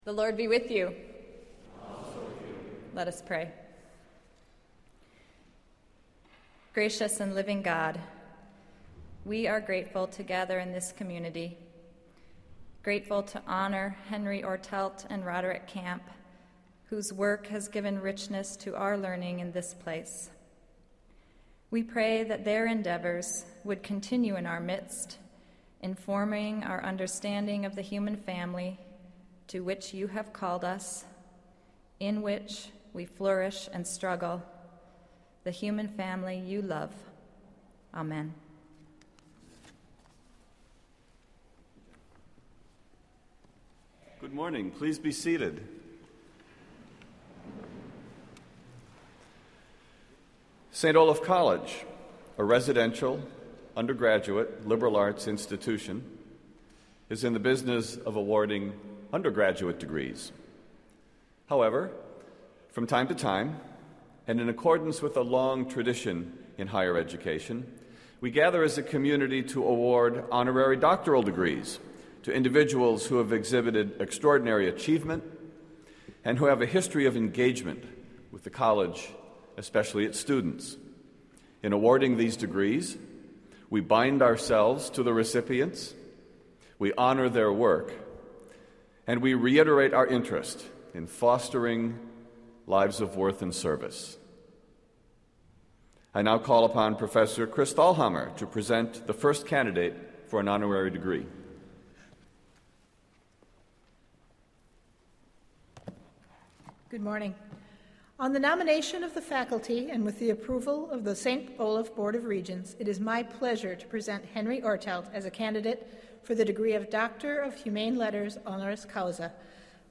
St. Olaf College — Chapel Service for Thu, Oct 15, 2009
Honorary Degree Convocation